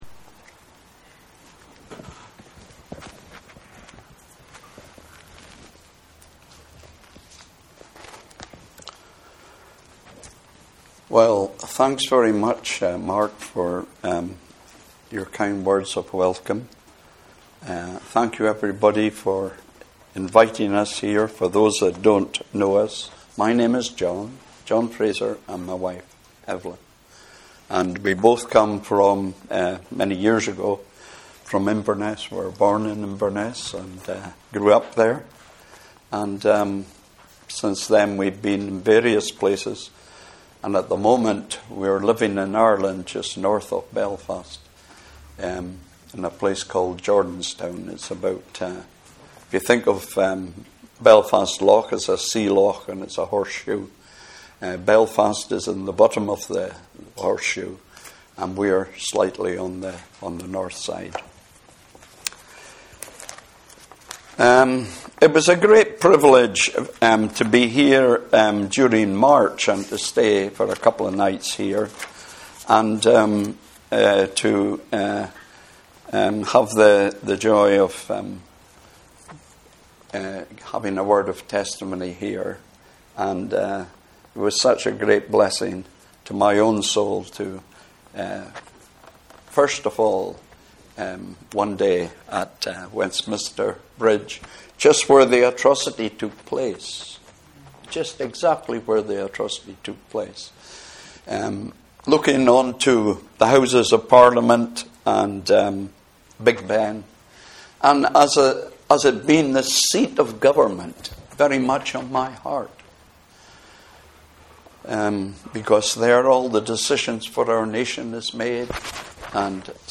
Passage: Psalm 46 Service Type: Sunday Evening Service « ‘Lord